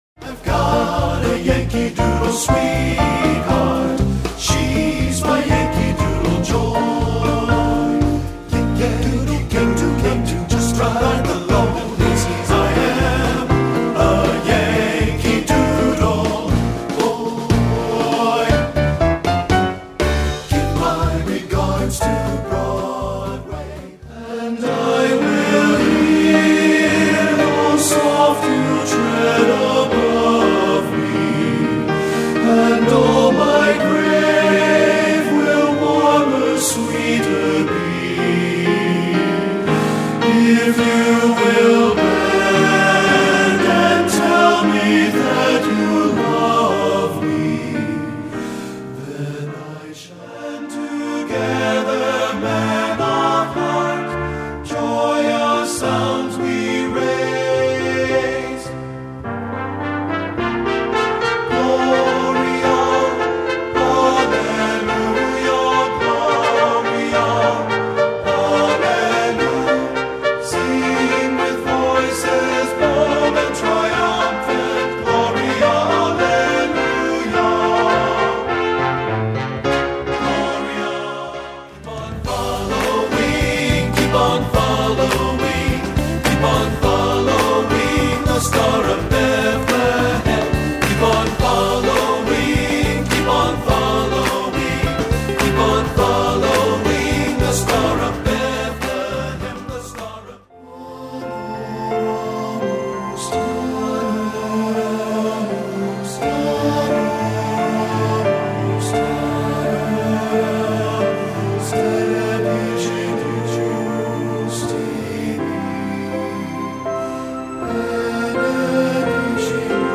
Choral Collections Male Chorus
for tenor and baritone voices